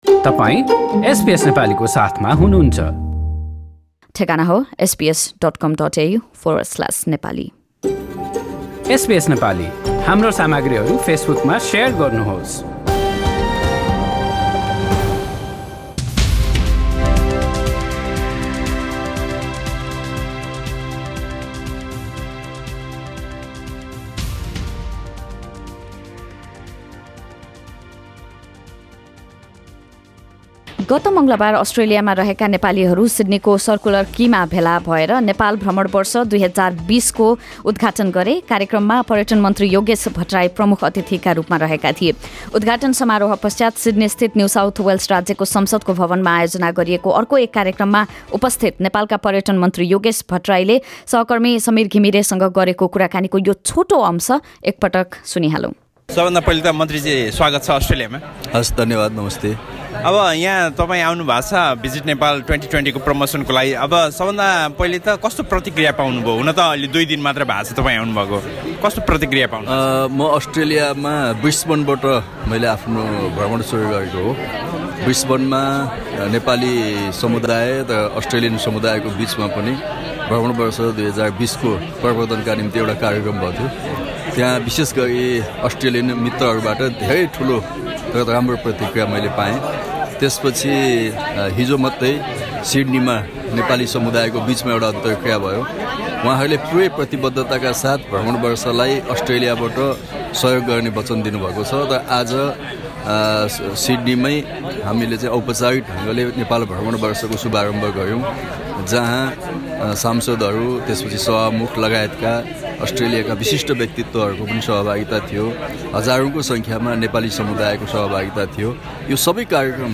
Nepali Minister for Tourism and Civil Aviation - Yogesh Bhattarai is currently in Australia launching the Visit Nepal Year 2020. Bhattarai spoke with SBS Nepali on his visit and the possibility to kick start Kathmandu Sydney flight.